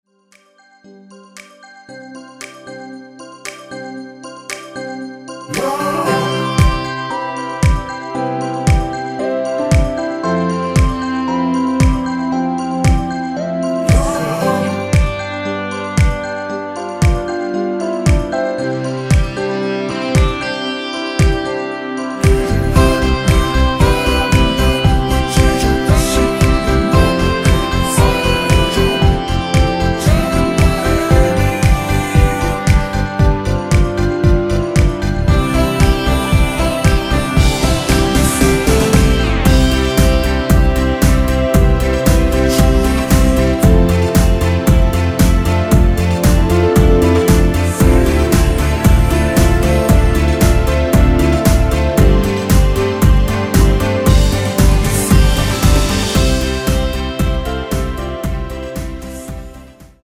-3)코러스 MR 입니다.
워어~ 등 순수 코러스만 있습니다.
엔딩이 페이드 아웃이라 노래 부르기 좋게 엔딩 만들었습니다.
◈ 곡명 옆 (-1)은 반음 내림, (+1)은 반음 올림 입니다.